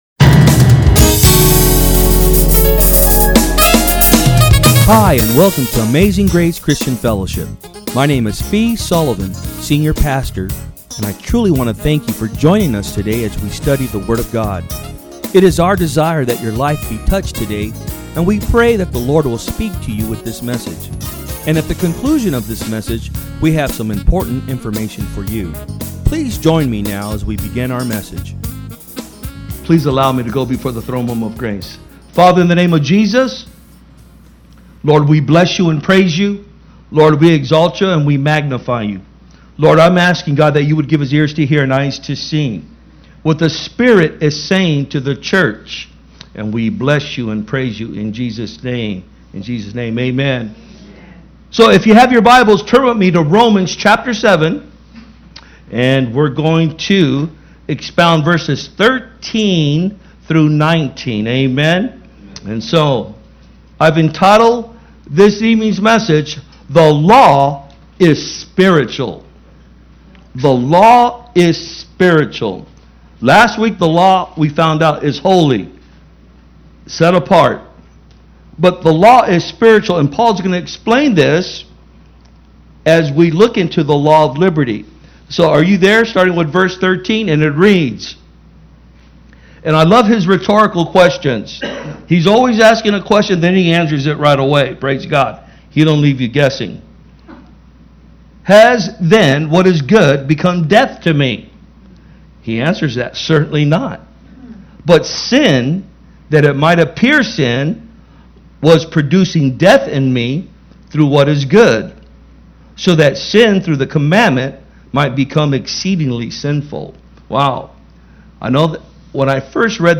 From Service: "Wednesday Pm"